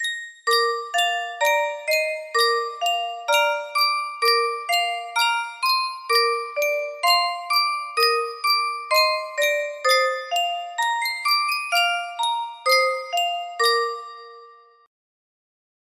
Sankyo Music Box - O Come All Ye Faithful HH music box melody
Full range 60